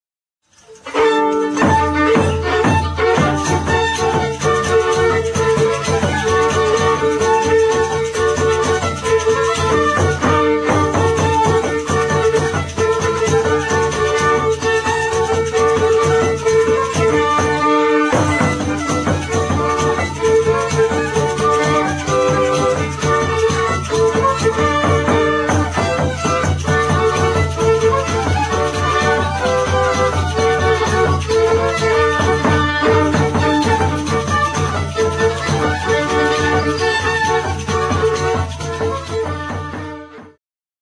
Polka
nagr. Zdziłowice, 2004
– 1 skrzypce
bębenek